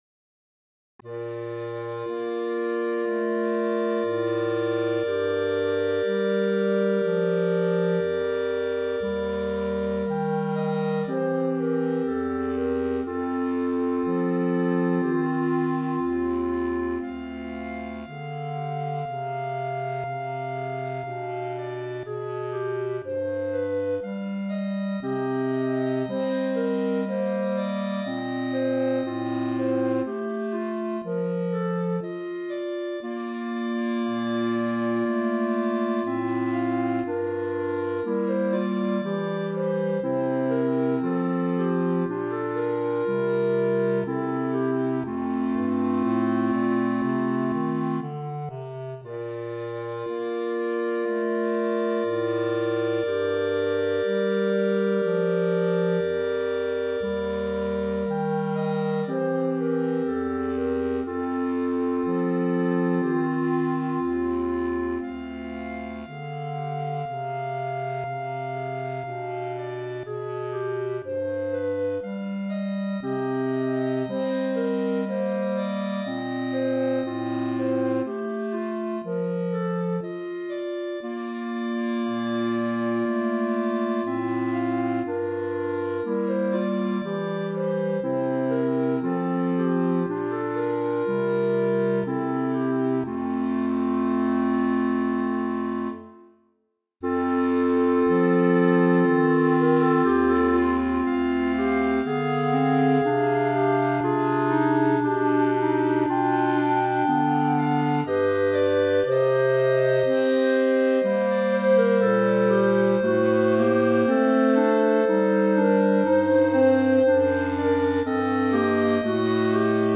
Voicing: Clarinet Quartet